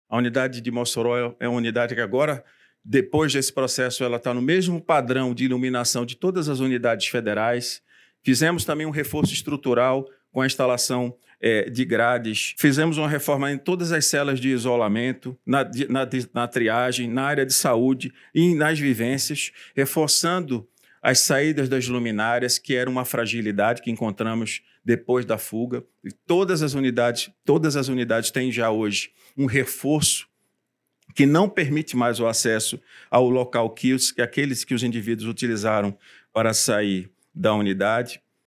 André Garcia, Secretário Nacional de Políticas Penais, fala sobre as melhorias feitas na Penitenciária Federal em Mossoró — Ministério da Justiça e Segurança Pública